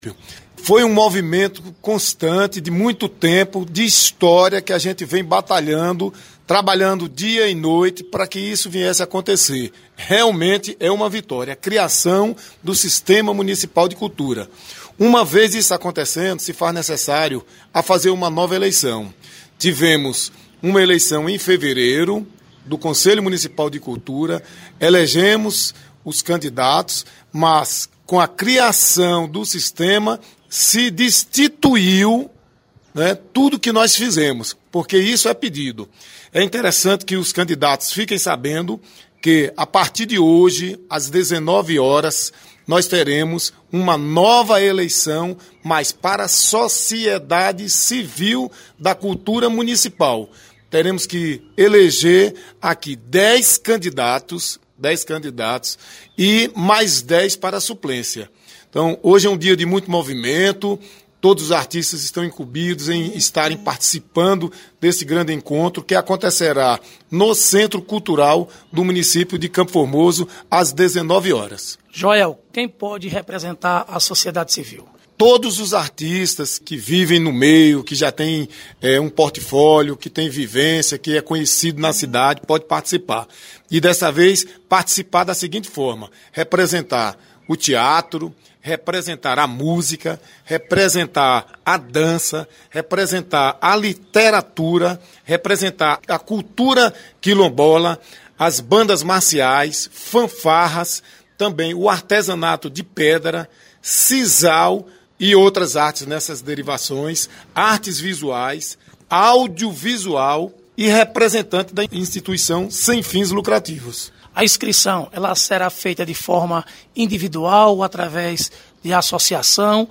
Secretário de cultura, Joel de Sena faz orientações sobre a eleição dos membros do conselho municipal de cultura